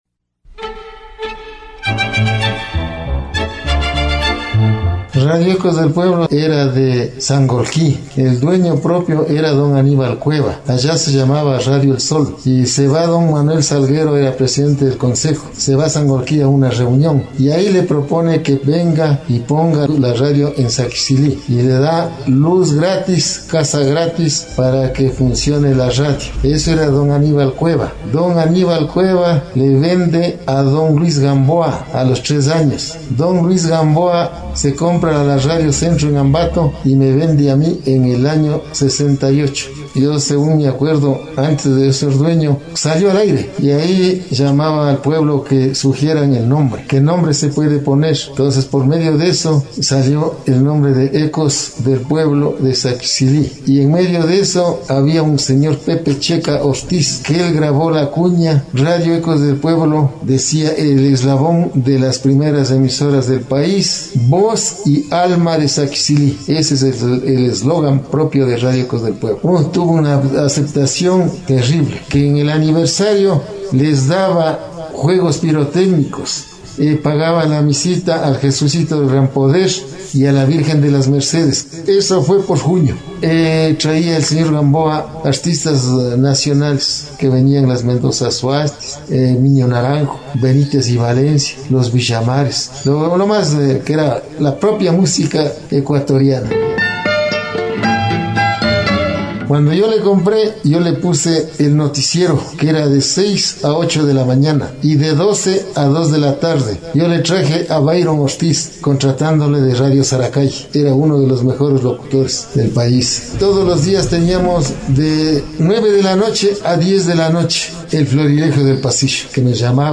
Presentación del Duo Benitez y Valencia, transmisión de Radio Ecos del Pueblo año 1974.